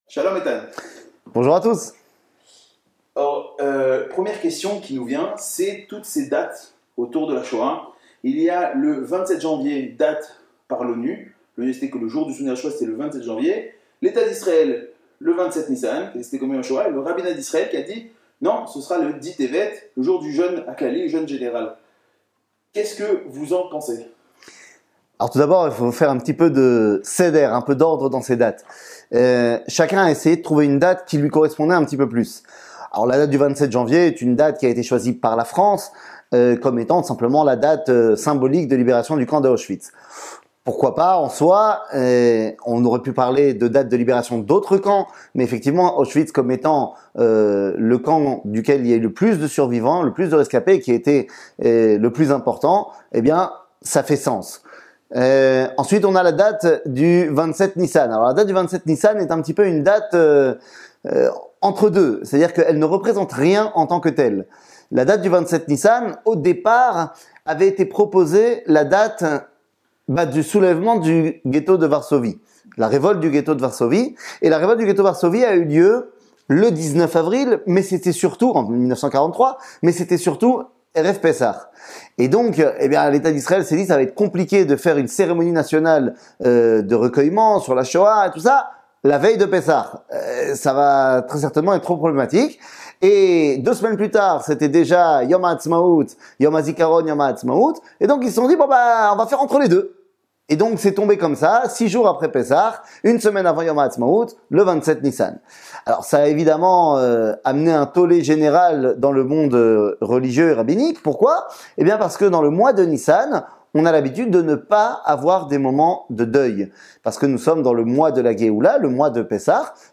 שיעור מ 17 אפריל 2023 58MIN הורדה בקובץ אודיו MP3 (53.25 Mo) הורדה בקובץ וידאו MP4 (145.46 Mo) TAGS : שיעורים קצרים